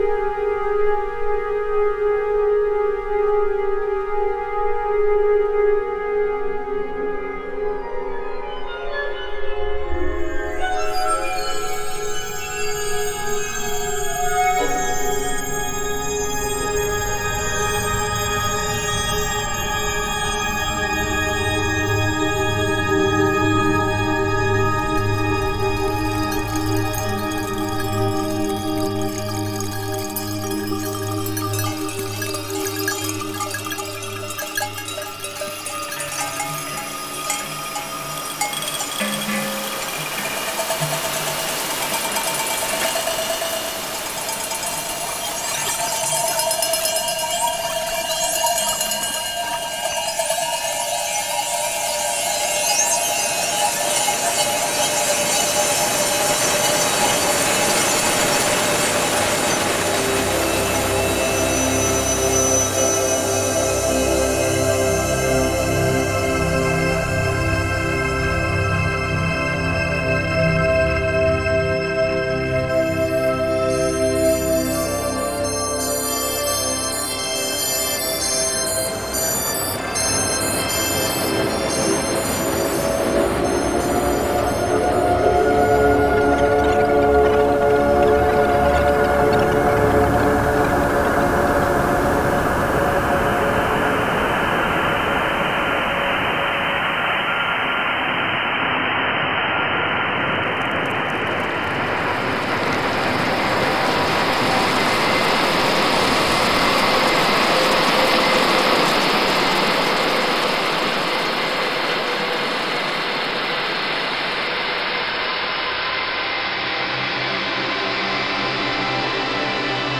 From Cinematic and Experimental through to Drone, Ambient and beyond, enrich your productions with this artfully assembled, 670MB collection of 150 royalty-free loops, bursting with detail, texture and dense, absorbing sonic atmosphere.
Field recordings, shortwave radio static, analog synths and even acoustic instruments have been intensively warped through our bespoke spectral and granular processing chains, producing the 12 core arrays of loops at the heart of the pack which can themselves be mixed and matched to your heart’s content.
Perfectly looping for 8 bars at either 100 or 120 Bpm and key-labelled wherever tonal material appears, this deeply evocative sound collection will pour interstellar aural atmospheres, smooth synth drones, flickering granular layers, complex noise textures and propulsive SFX samples down on your unsuspecting DAW sessions.
•34 Texture Loops
•15 Drone Loops